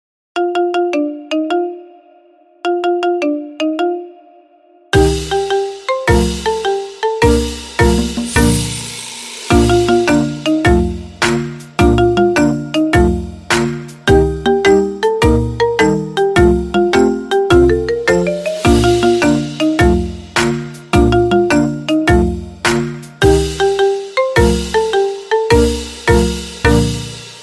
Ringtone Version iPhone
หมวดหมู่: เสียงเรียกเข้า